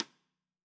Index of /musicradar/Snares/Sonor Force 3000